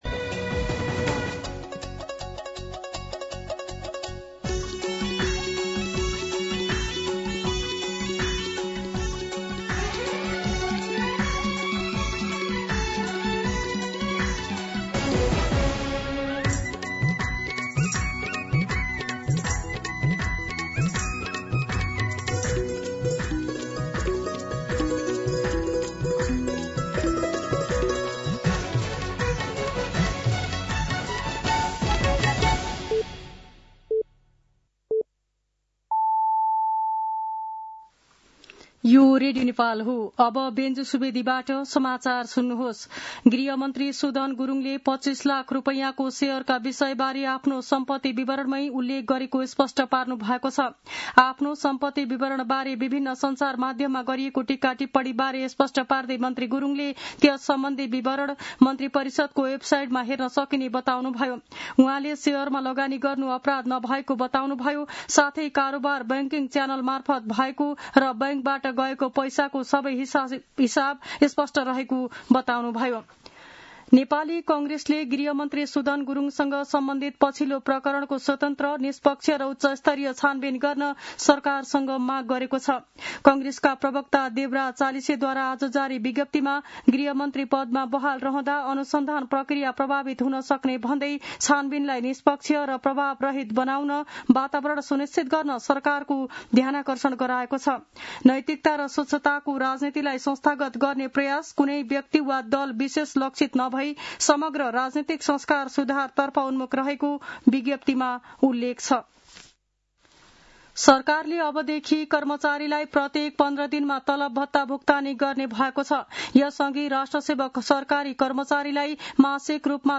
An online outlet of Nepal's national radio broadcaster
दिउँसो ४ बजेको नेपाली समाचार : ७ वैशाख , २०८३
4pm-News-07.mp3